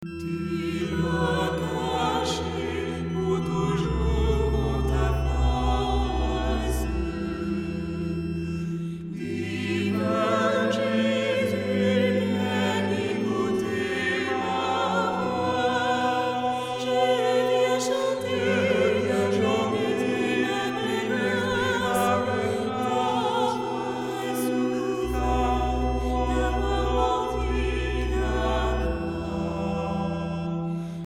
Un album enregistré en polyphonie